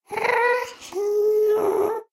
moan6.wav